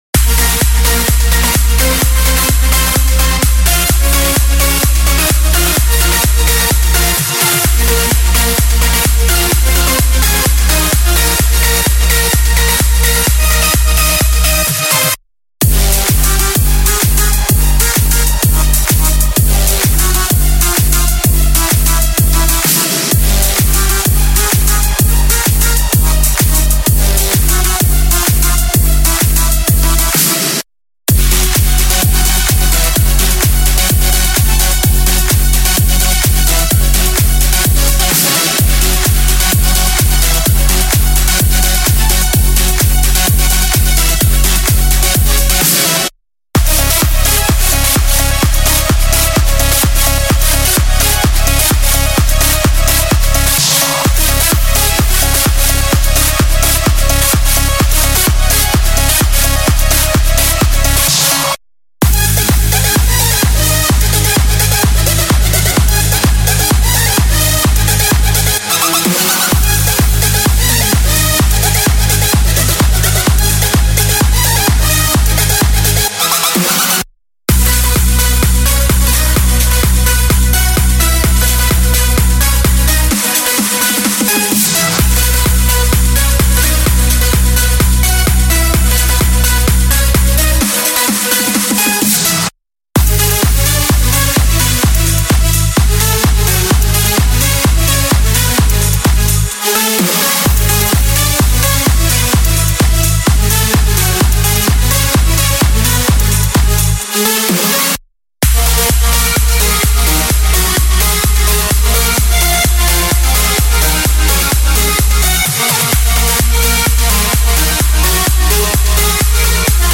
House
件都为您提供歌曲创意，以开始您的下一个EDM热门歌曲，包括低音，主音，弹奏，和弦和打击垫。
– 128 BPM
-每个套件20个混合演示